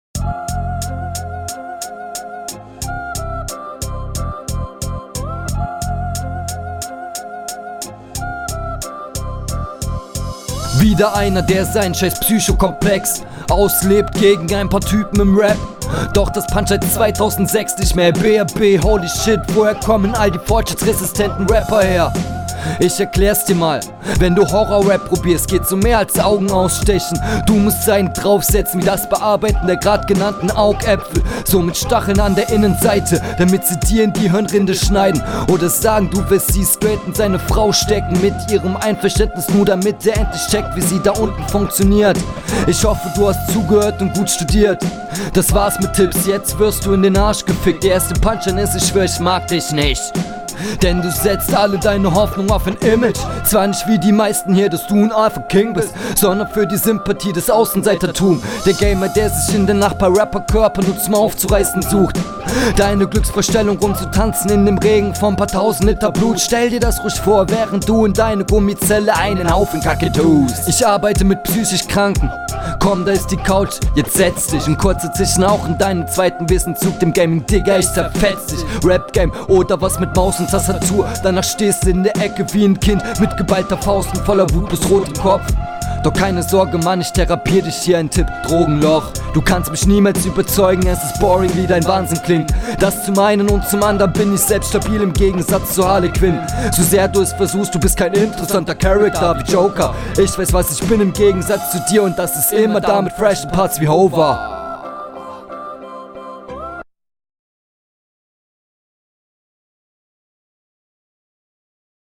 Aufjedenfall hast du dich bisschen mit mische auseinander gesetzt klingt schon besser als sonst :) …